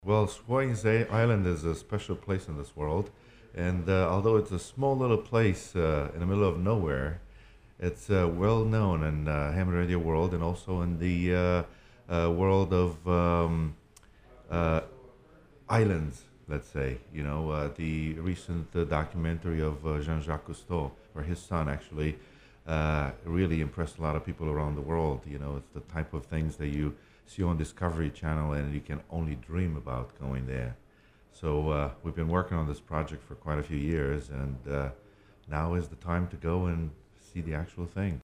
one of the HAM Radio operators who will be voyaging to Swains.